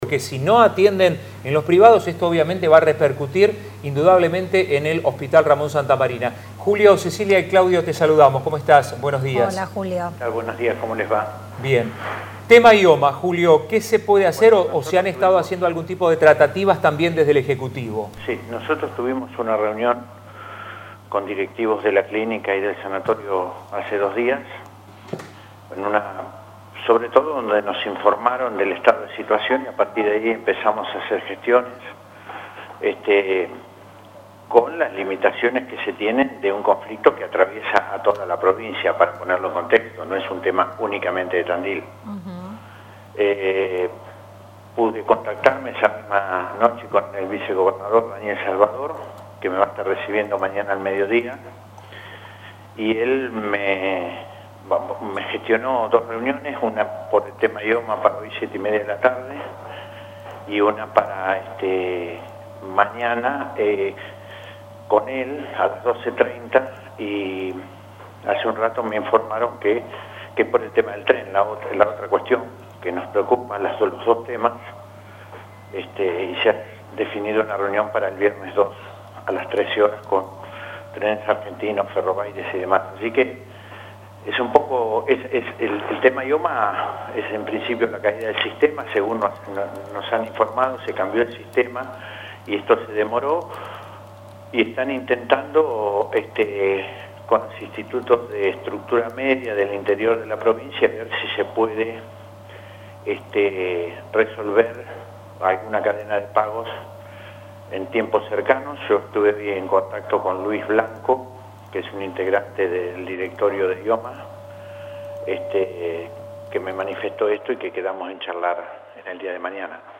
Así lo detalló Julio Elichiribehety en diálogo con “La Ciudad”. El jefe de gabinete se reunirá este jueves con representantes de Provincia por el tema IOMA y el viernes el encuentro será con el vicegobernador provincial, Daniel Salvador, por el mismo tema y se añadirá en agenda la situación del tren a Tandil.